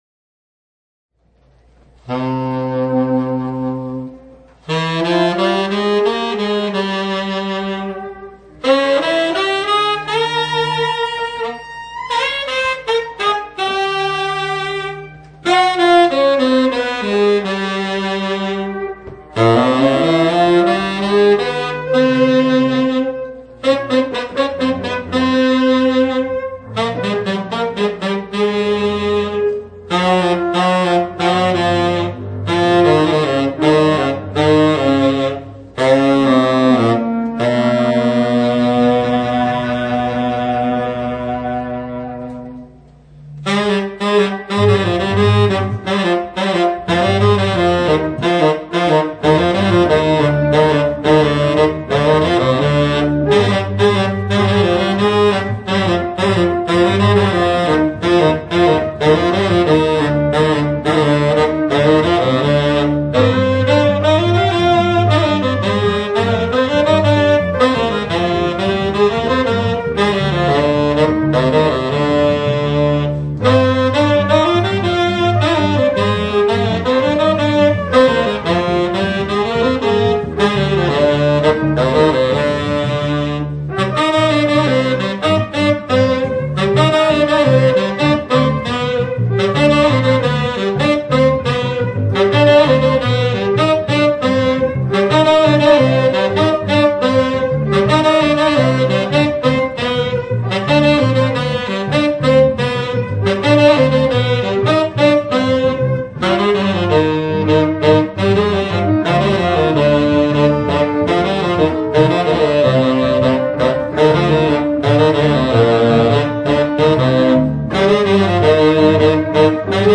Recorded live April 15, 2001 in Osaka, Japan